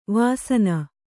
♪ vasna